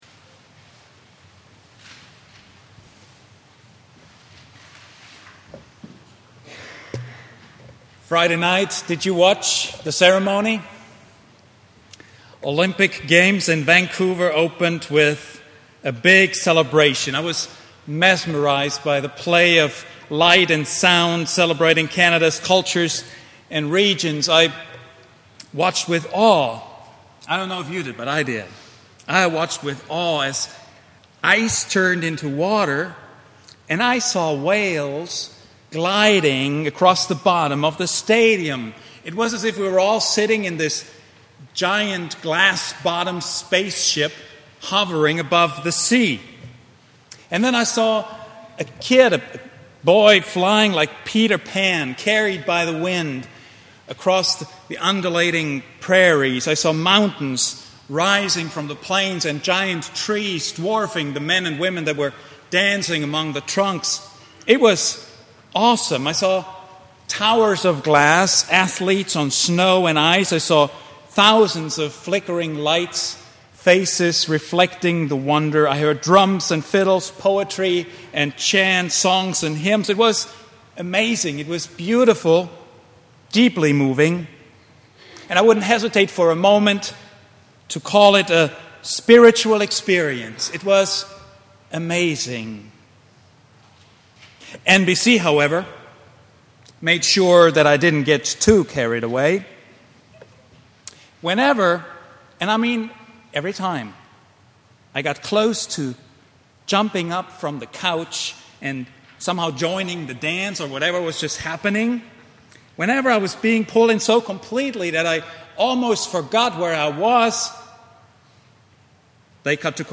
Glory in the Gray — Vine Street Christian Church